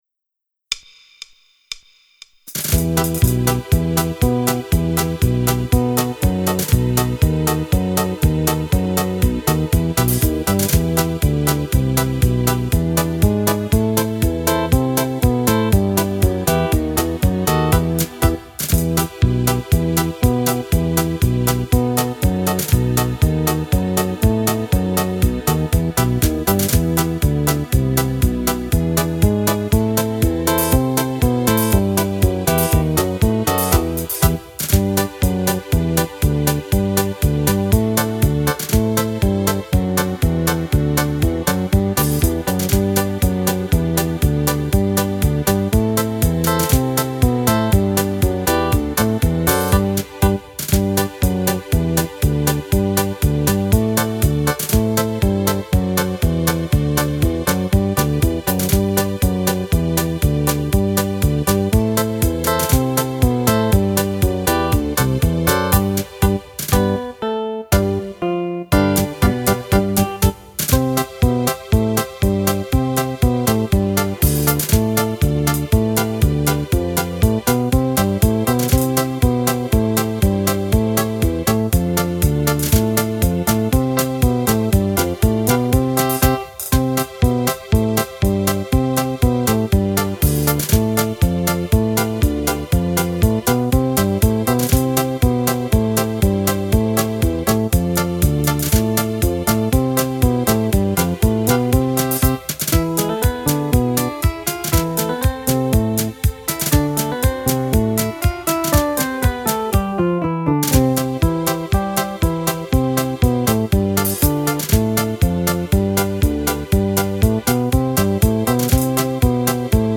Polca di bravura
Fisarmonica